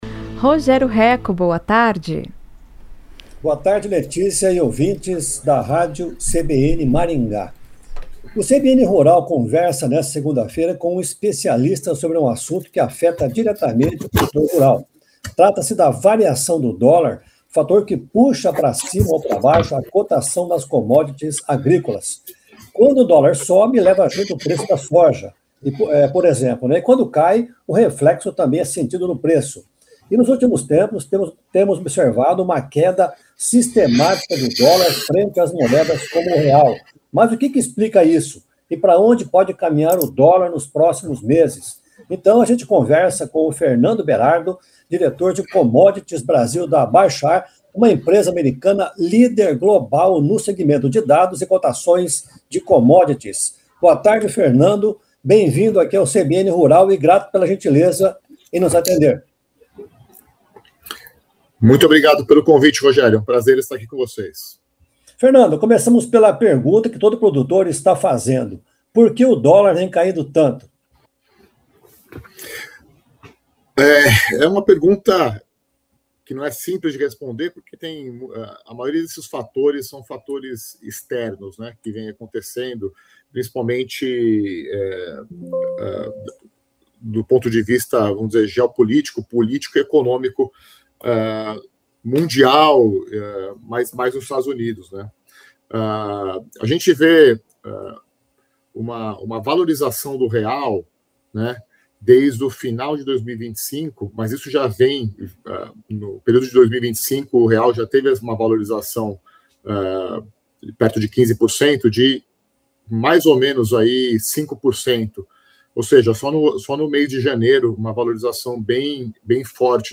O CBN Rural conversou nesta segunda-feira com um especialista sobre um assunto que afeta diretamente o produtor rural.